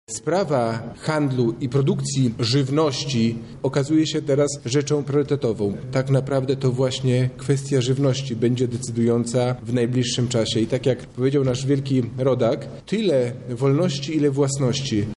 Wicewojewoda Robert Gmitruczuk odniósł się również do problemu sprzedaży ziemi obcokrajowcom: